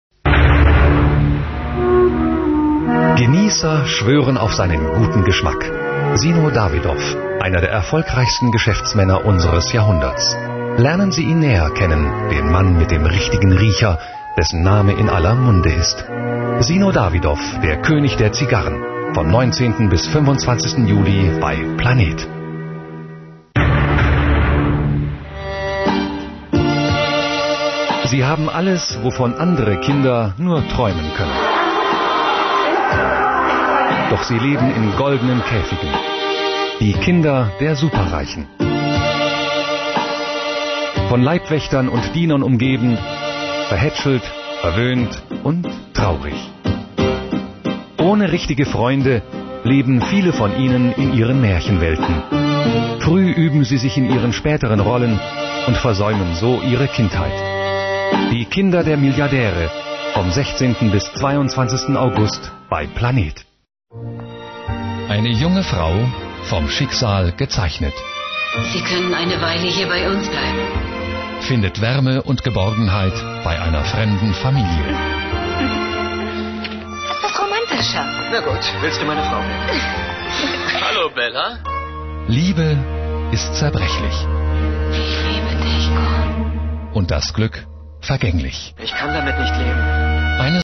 Kein Dialekt
Sprechprobe: Werbung (Muttersprache):
german voice over artist